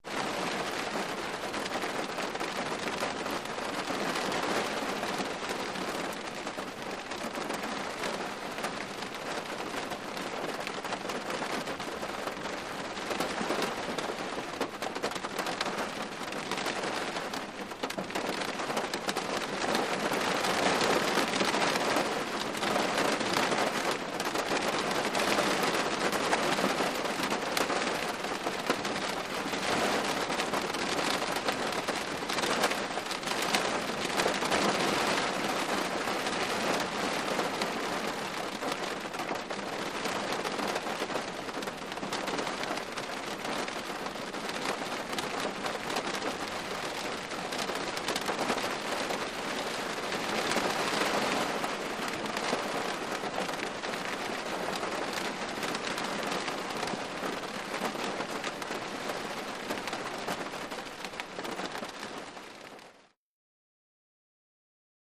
Rain On Car; Steady, Interior Perspective